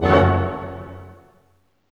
Index of /90_sSampleCDs/Roland LCDP08 Symphony Orchestra/HIT_Dynamic Orch/HIT_Orch Hit Min
HIT ORCHMI0A.wav